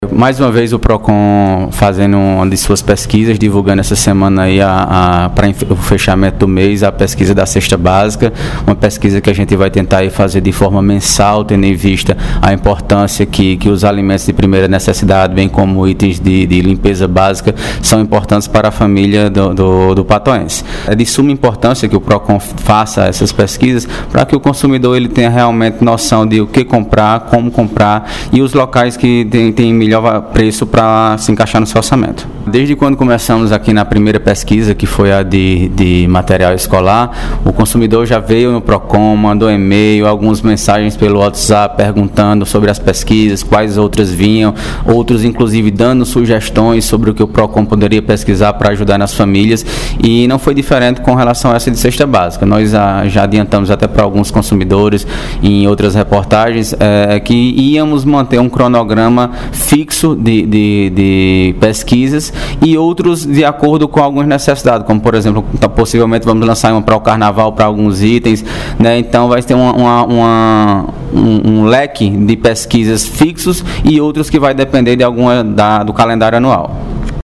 Fala do Secretário do PROCON, Bruno Maia –